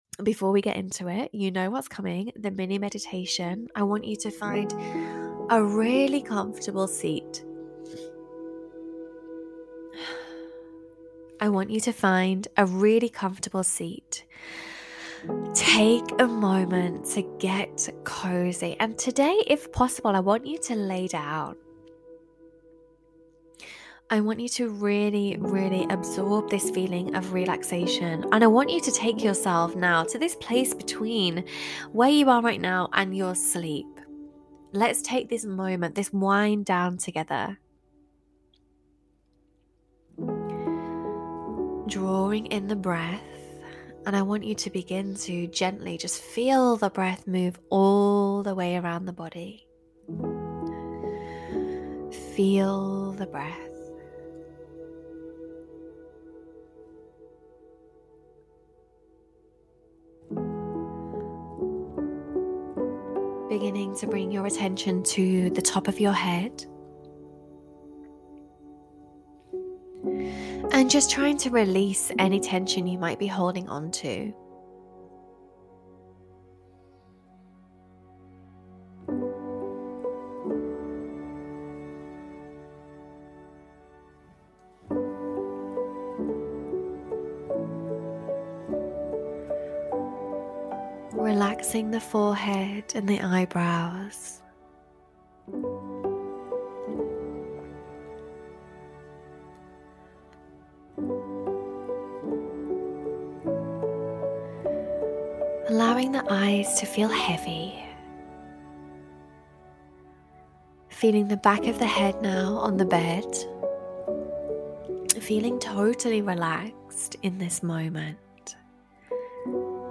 Mini Meditation for Sleep